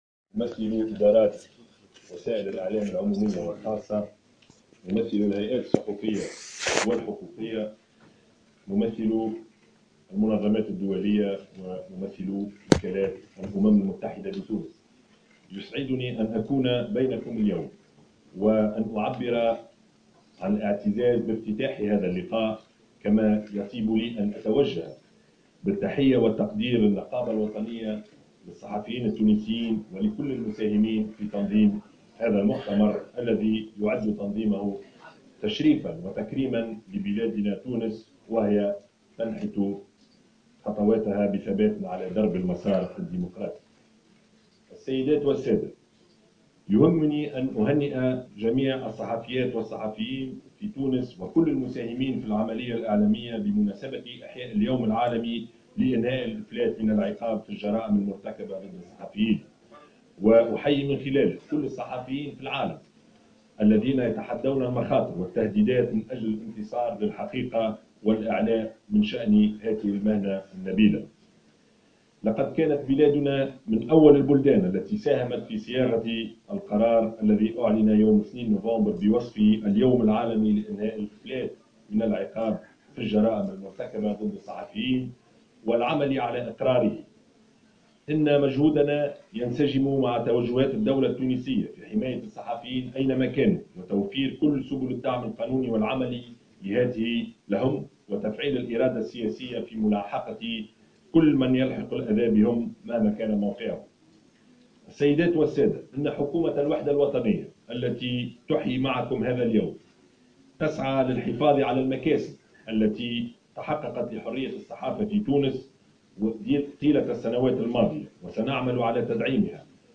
قال رئيس الحكومة يوسف الشاهد في كلمة له، في افتتاح ورشة عمل حول سلامة الصحفيين في تونس من تنظيم النقابة الوطنية للصحفيين التونسيين بمناسبة اليوم العالمي ضد الإفلات من العقاب في الجرائم المرتكبة ضد الصحفيين، اليوم الأربعاء، في العاصمة، إن حكومته ستسعى للحفاظ على المكاسب التي تحققت للصحفيين طيلة السنوات الماضية، وتدعيمها وتفعيلها.